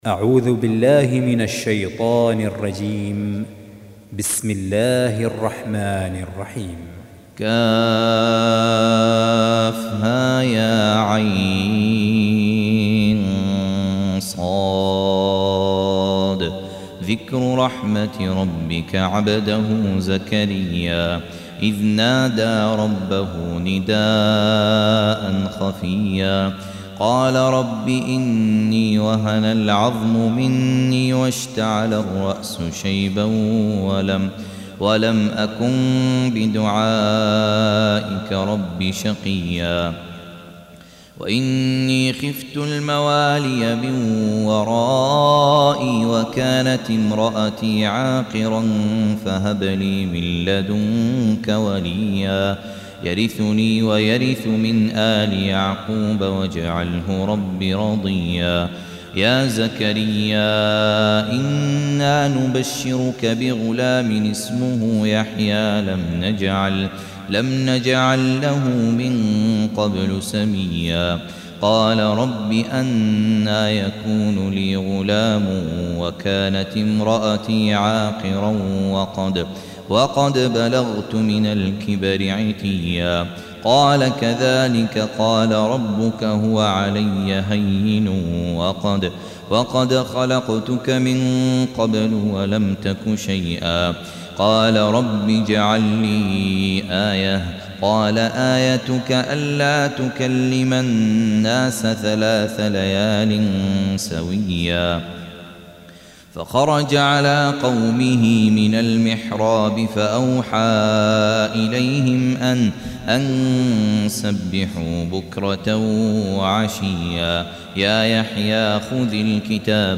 Surah Repeating تكرار السورة Download Surah حمّل السورة Reciting Murattalah Audio for 19. Surah Maryam سورة مريم N.B *Surah Includes Al-Basmalah Reciters Sequents تتابع التلاوات Reciters Repeats تكرار التلاوات